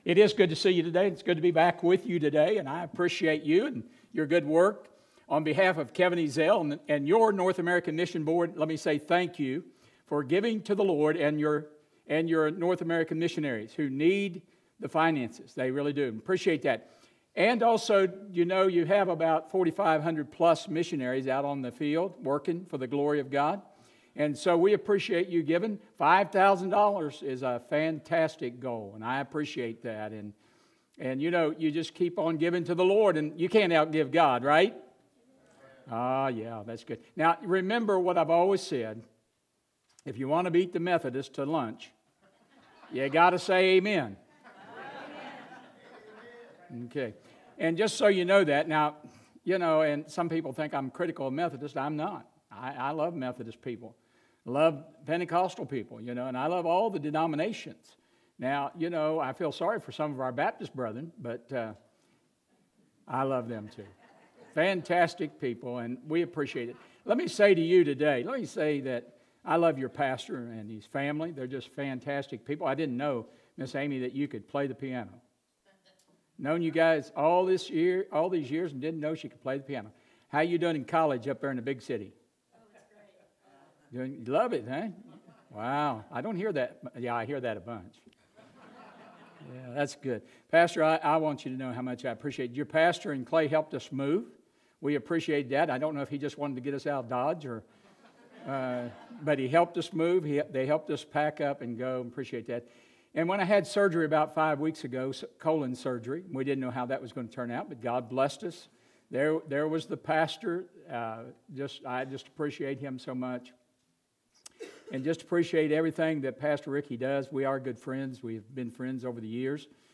This Sunday morning sermon was recorded on February 28th, 2021.